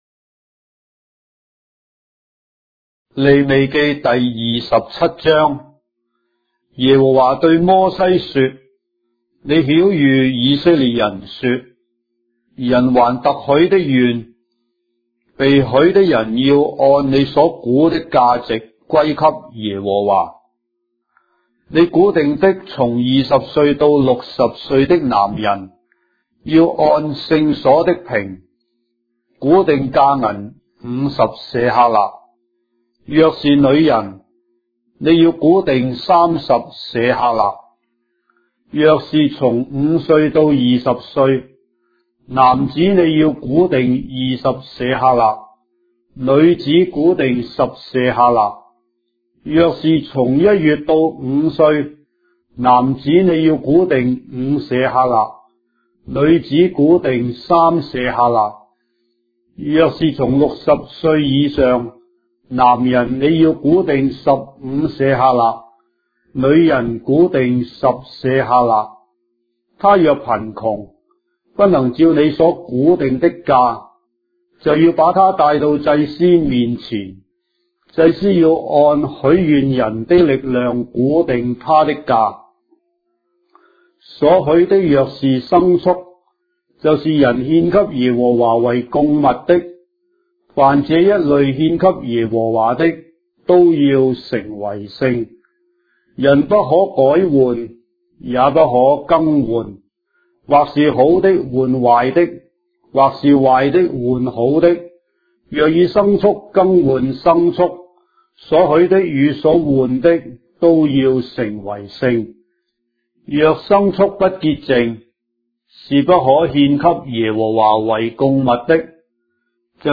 章的聖經在中國的語言，音頻旁白- Leviticus, chapter 27 of the Holy Bible in Traditional Chinese